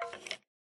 skeleton2.ogg